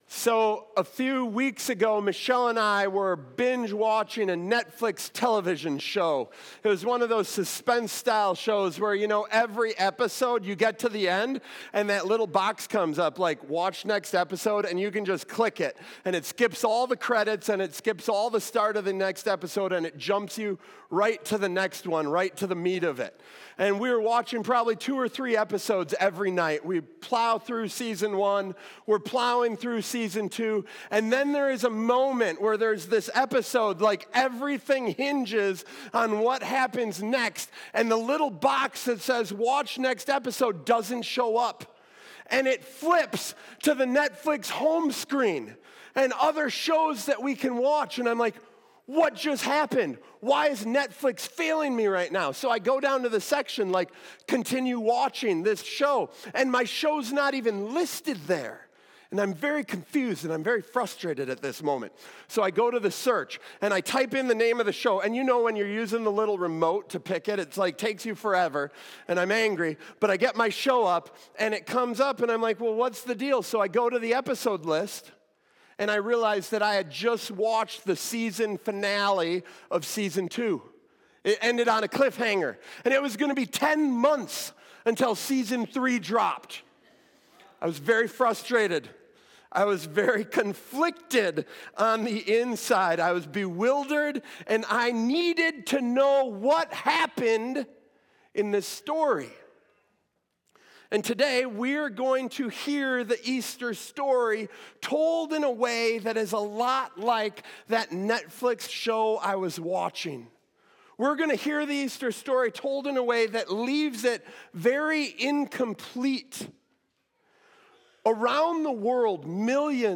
In this Easter message, we explore Mark 16:1-8, the most unusual resurrection account in the Bible.